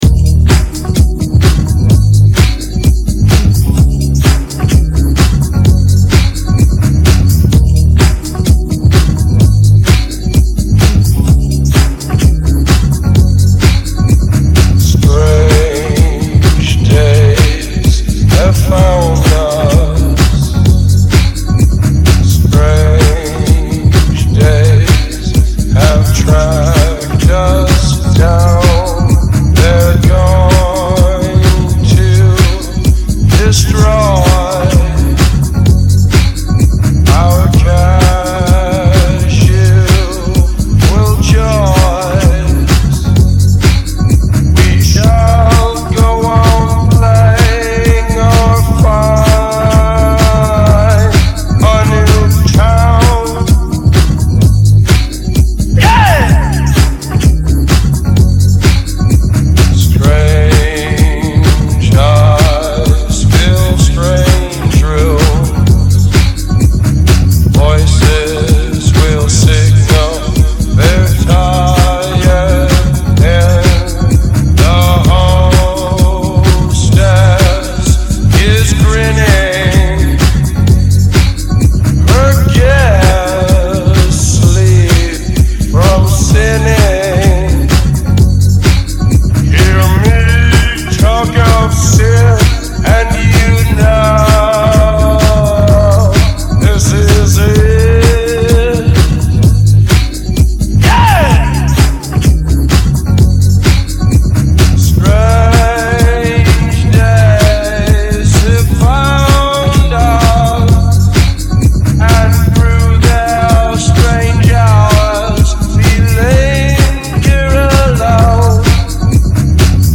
My mashup